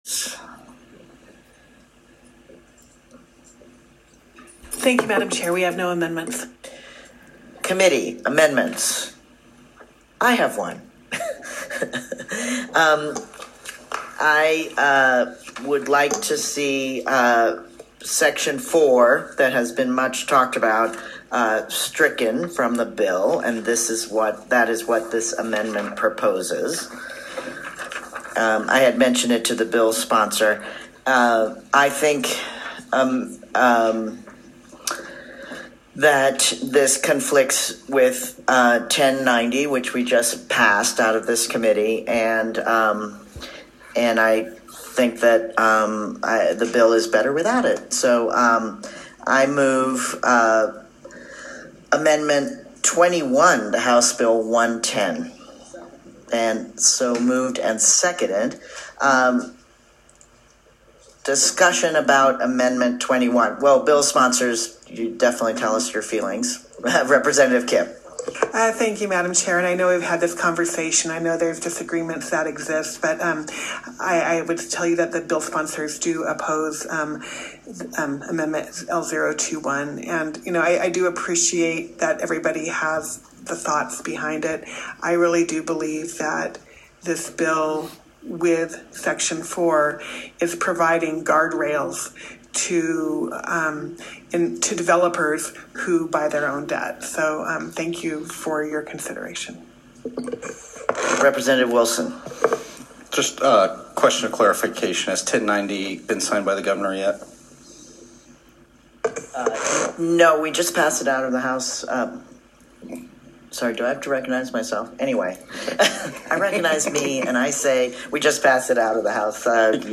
Then, here is Representative Taggart testifying during a hearing on his “transparency” bill, that the transparency part is worthless.  He asserts that  if you take out section 4  –  which legalizes developers with a conflict of interest buying their own debt paid by the residents – if you take out that section 4, you gut the bill.